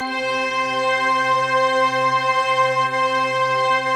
SI1 BELLS09R.wav